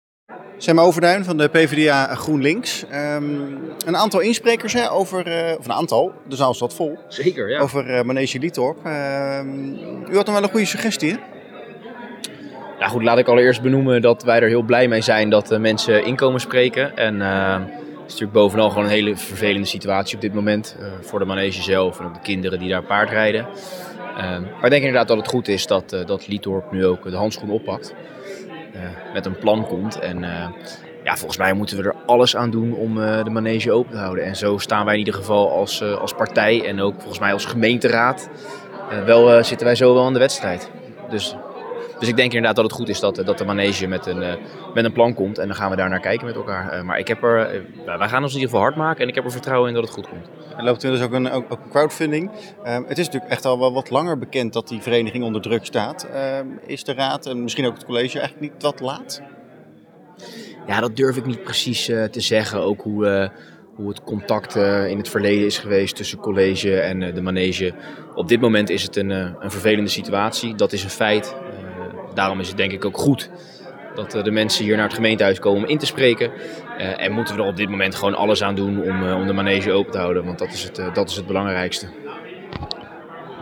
AUDIO: Sem Overduin van PvdA-GroenLinks over Liethorp.